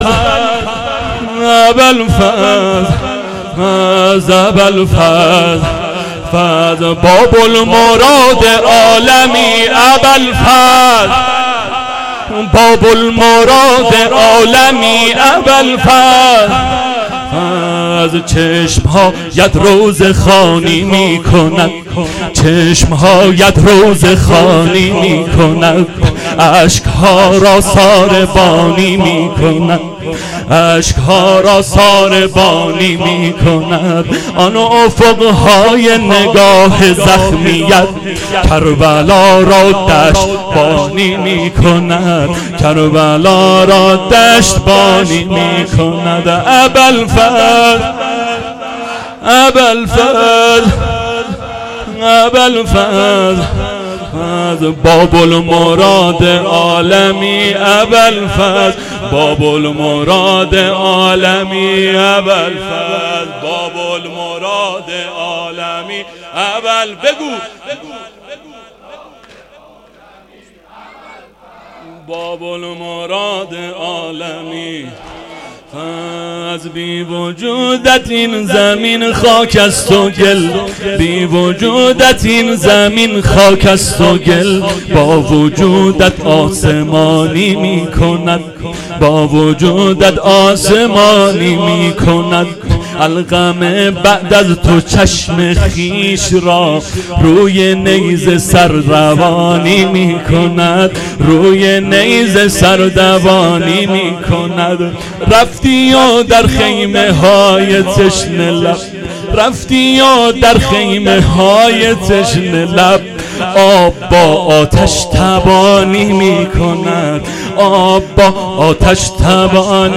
شب نهم محرم ۹۷